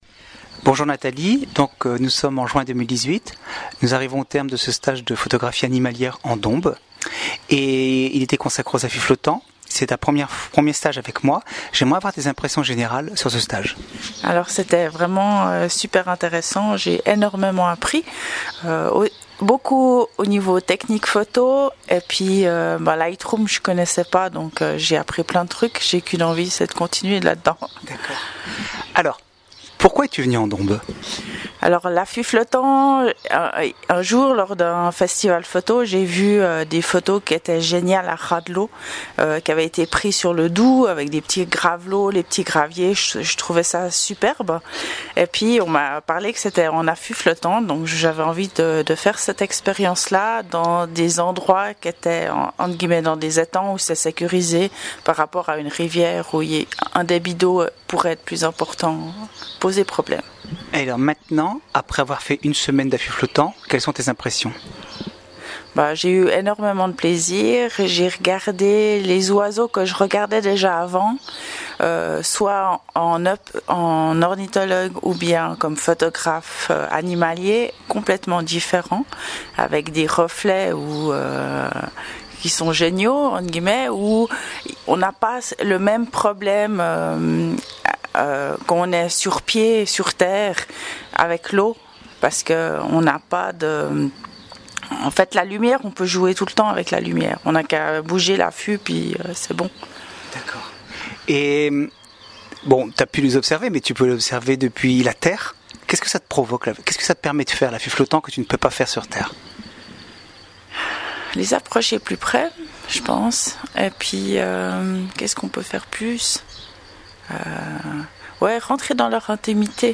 Témoignages écrits et oraux des participants